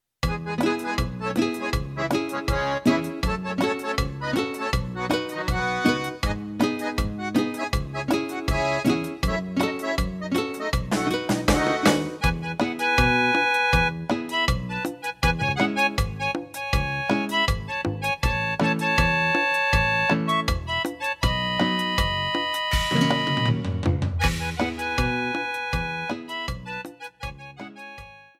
29-Cumbia-Nrt2.mp3